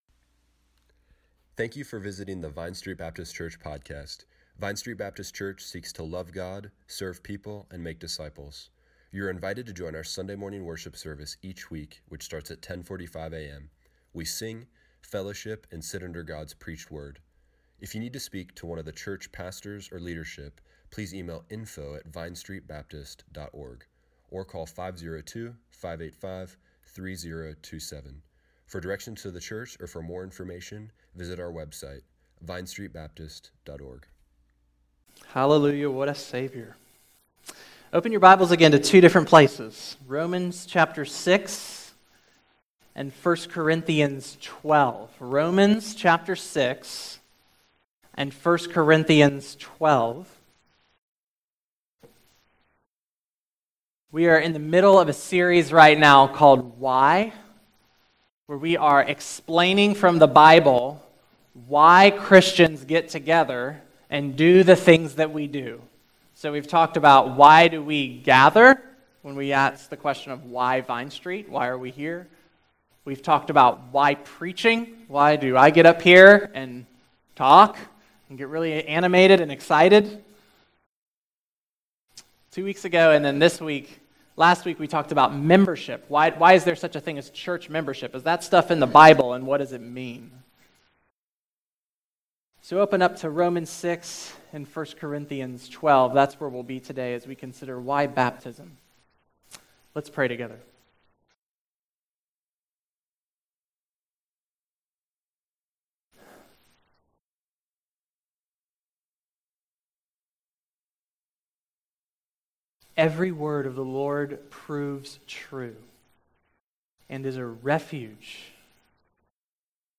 September 10, 2017 Morning Worship | Vine Street Baptist Church